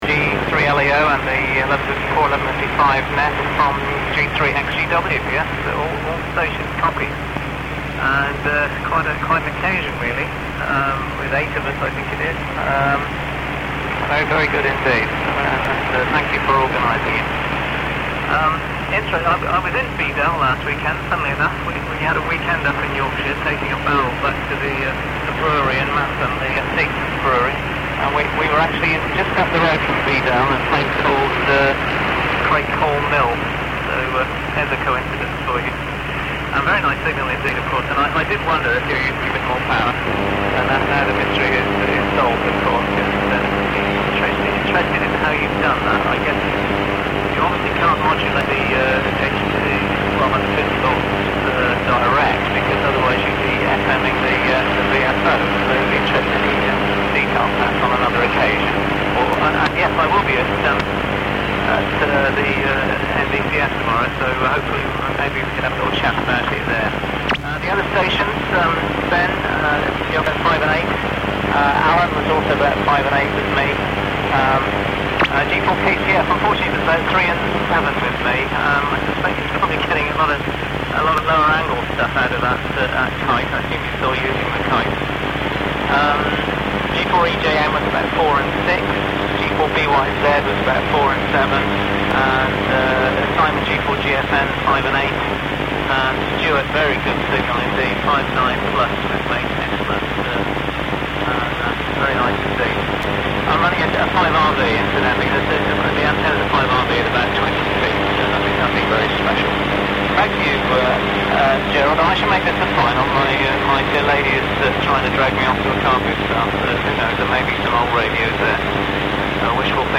I did find, however, that the transmitter really needs to be "talked up", even to the point of apparent over-modulation.
Later on I did a SPICE model of the modulation circuit and discovered a steadily rising frequency response starting at about 800 cycles. I guess that is the characteristic "1154 sound".
The whole station is now up and running and can be heard on 3615kHz when conditions are suitable.